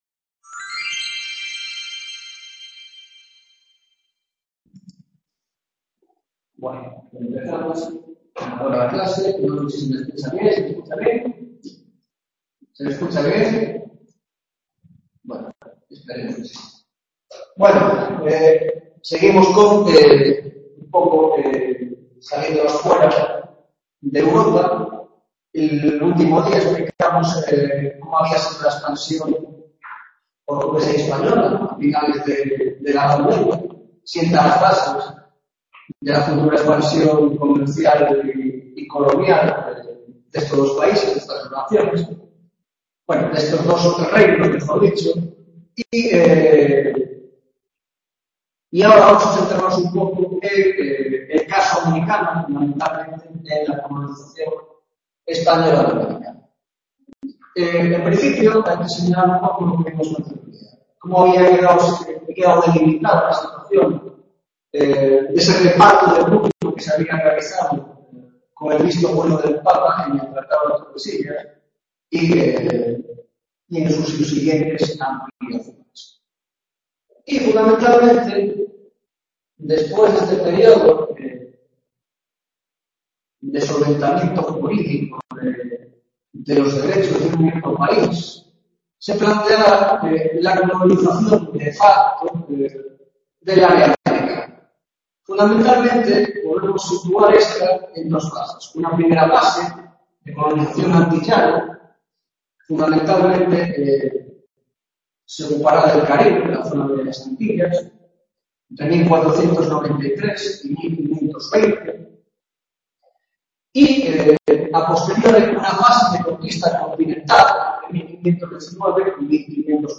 5ª tutoría de Historia Moderna, Grado de Antropología - Conquista y colonización de America